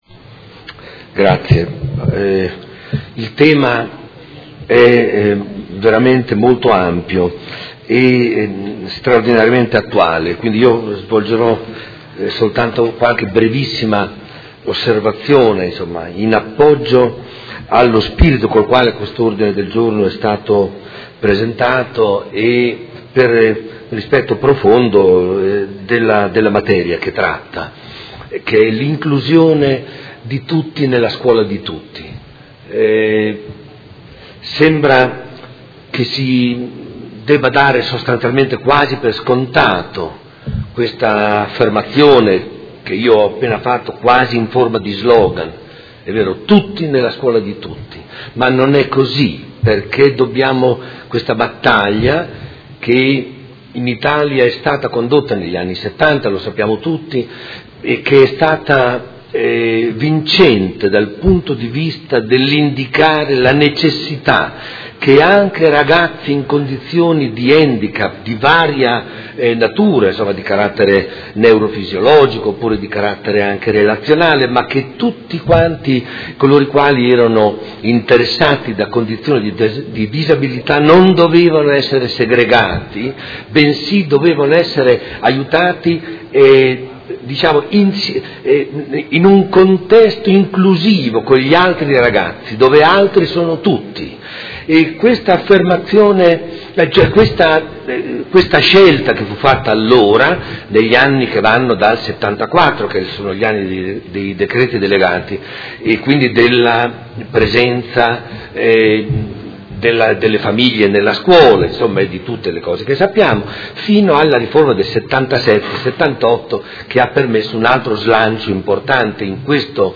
Seduta del 20/07/2017 Dibattito.